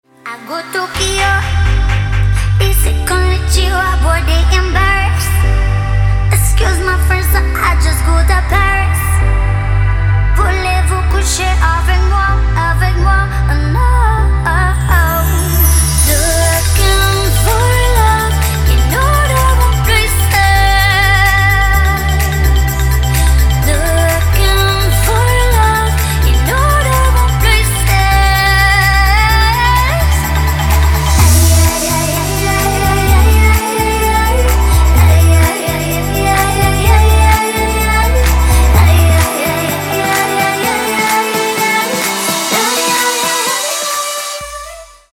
• Качество: 192, Stereo
женский вокал
dance
club
волшебные
vocal